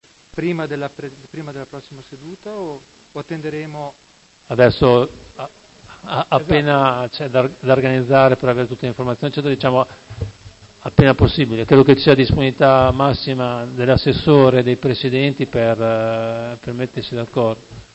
Antonio Baldini — Sito Audio Consiglio Comunale
Seduta del 17/10/2019 Chiarimento su Mozione piscina Pergolesi